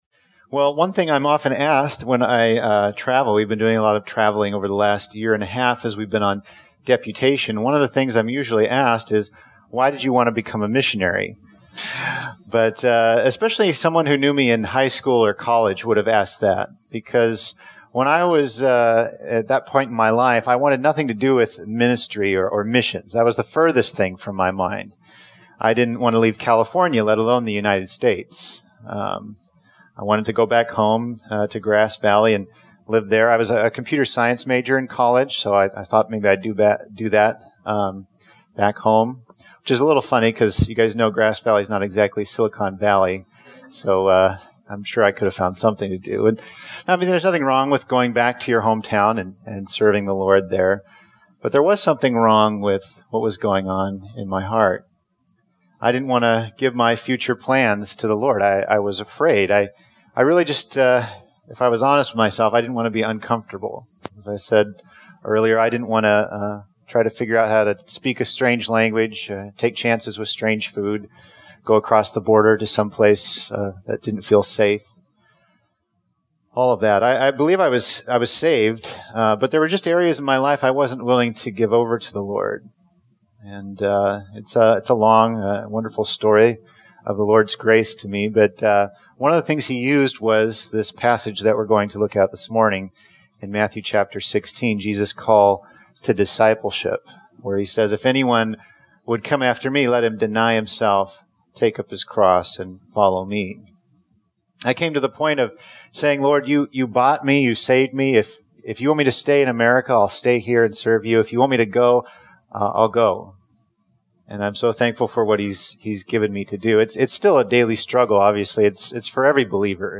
Considerations for True Disciples Guest Preacher